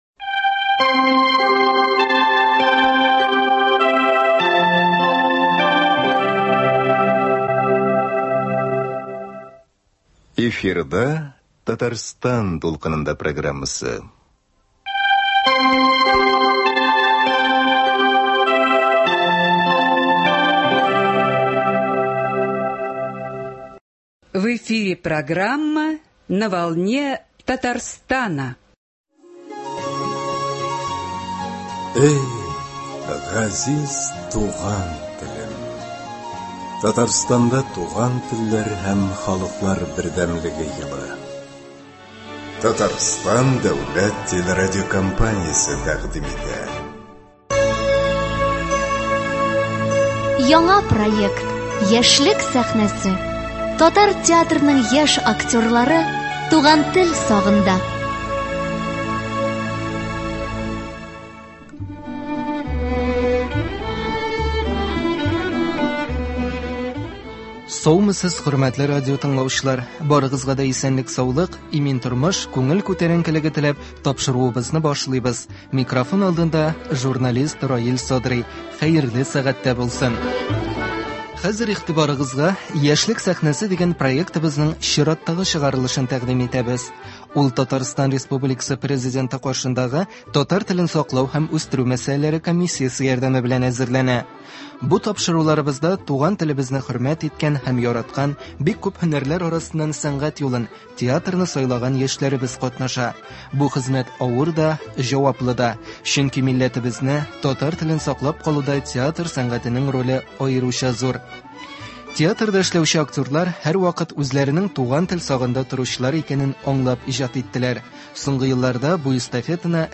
Дөресрәге, без аларның грим бүлмәсендә кунакта.